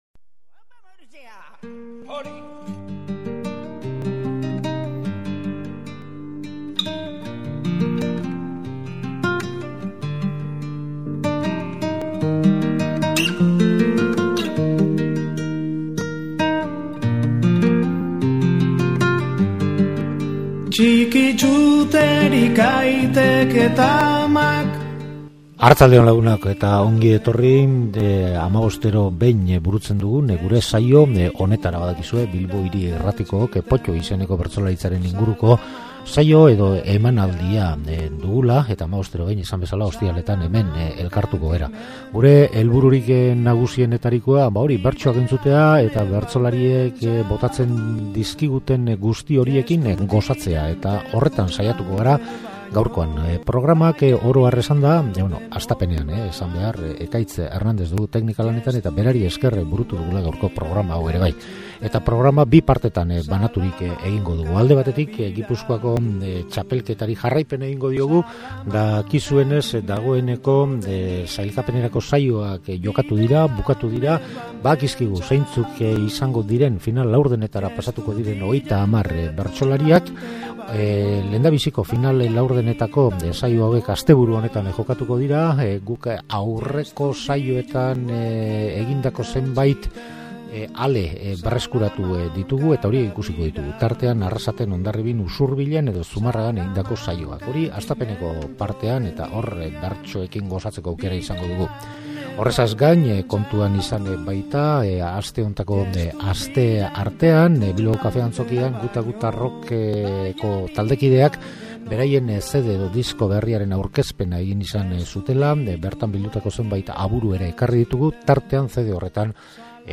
Gaurkoan Gipuzkoako txapelketan barna murgildu gara eta sailkapen saioetatik zenbait ale ekarri ditugu; Arrasaten, Hondarribin, Usurbilen eta Zumarragan bertsolari gazteek egindako lanak eskuragarri, beraz.